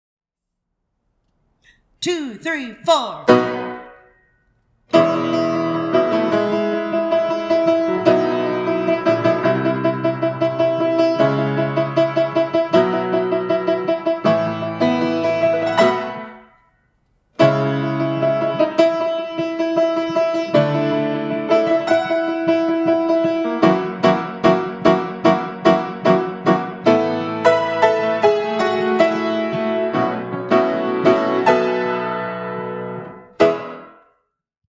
Accompaniment only (sing to this for taping)